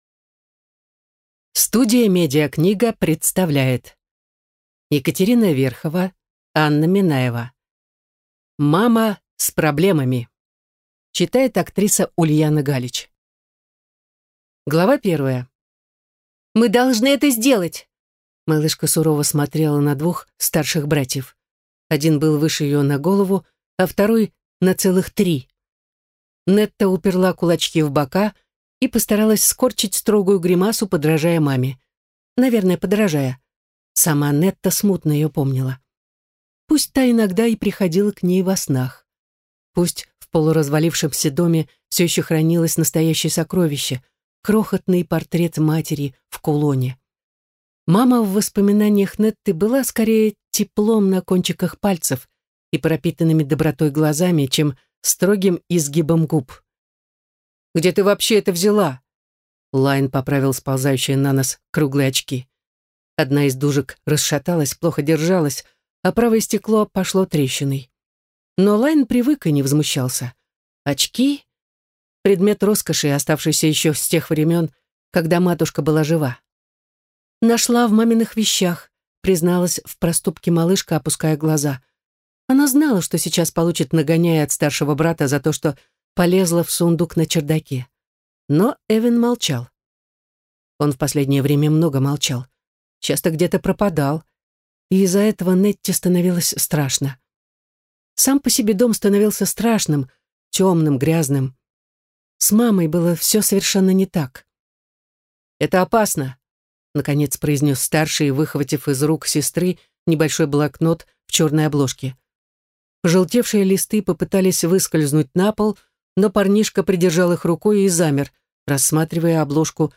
Книга прочитана популярной киноактрисой и известной актрисой дубляжа Ульяной Галич.